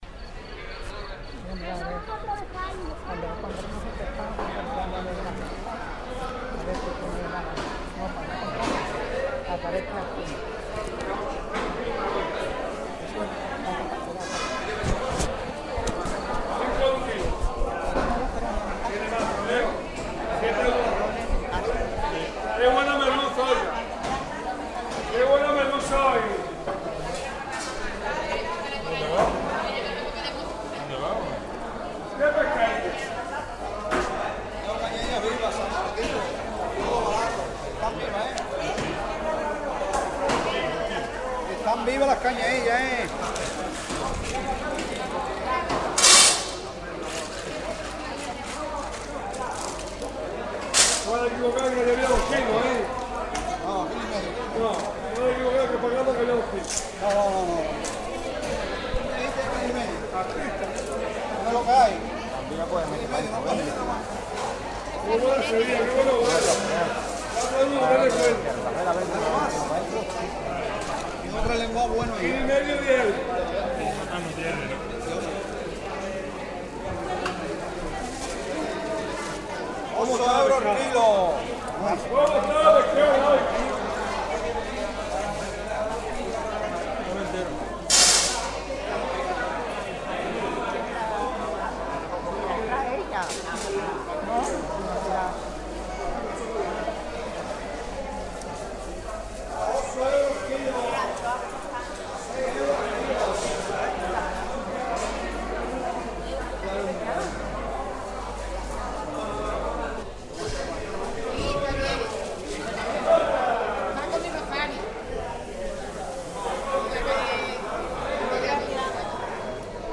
market-1.ogg